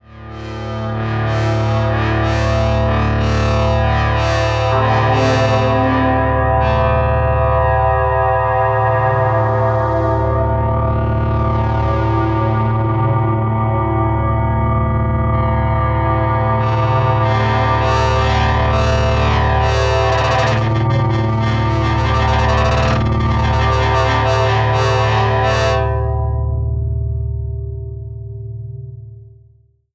FLAME "FM KOSMOS" Quad polyphonic FM synthesizer
9 - Moving Pad
9_MovingPad.wav